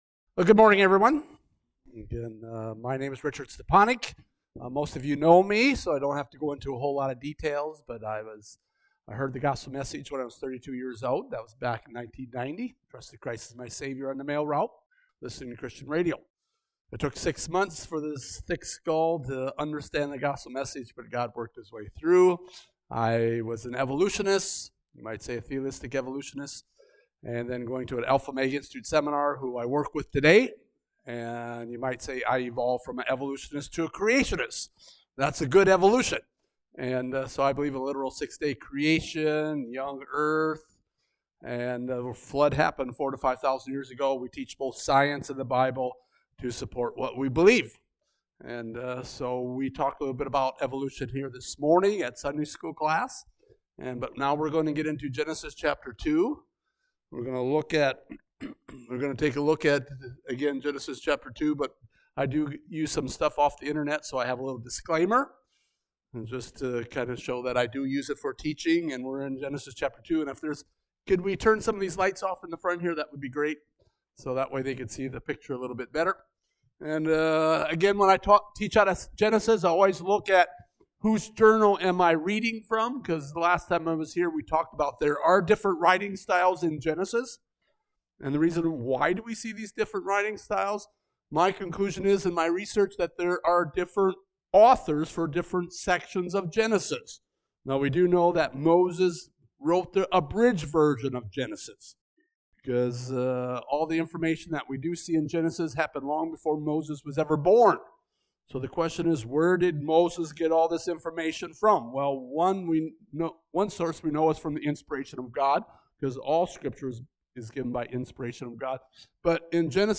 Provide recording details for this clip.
Passage: Genesis 2 Service Type: Morning Sevice